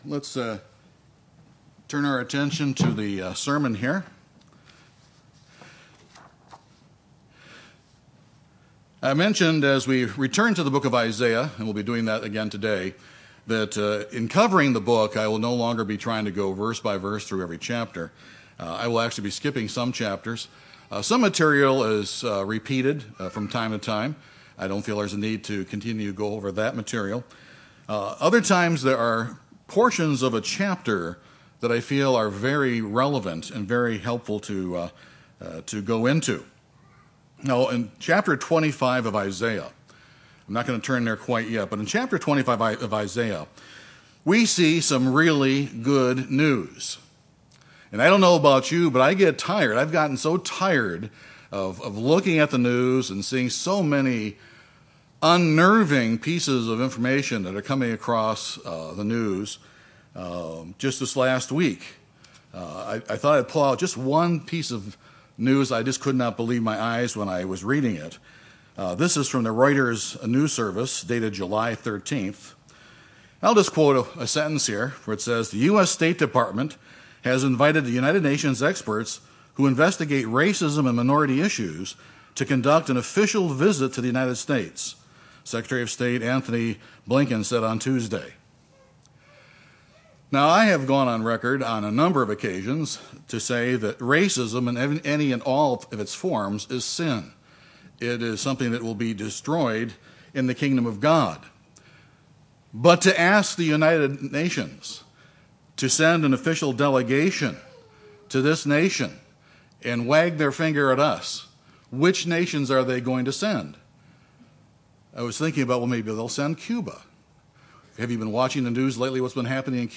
This sermon covers Isaiah 25:6-7 and discusses the destruction of the spiritual veil covering all nations.